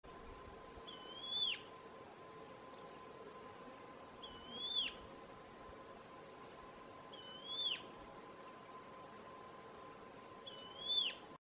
Batará Estriado (Myrmorchilus strigilatus)
Nombre en inglés: Stripe-backed Antbird
Localidad o área protegida: Parque Nacional Copo
Condición: Silvestre
Certeza: Observada, Vocalización Grabada